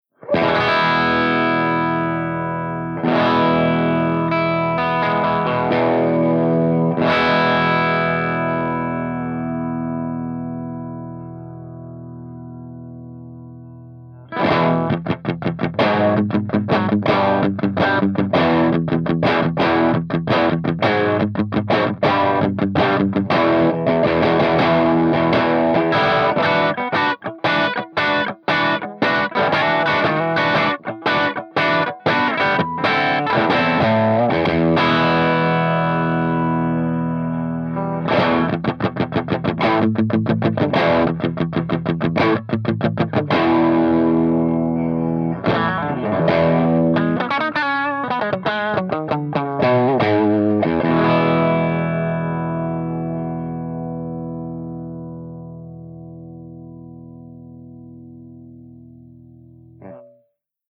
136_ROCKERVERB_CH2CRUNCH_V30_HB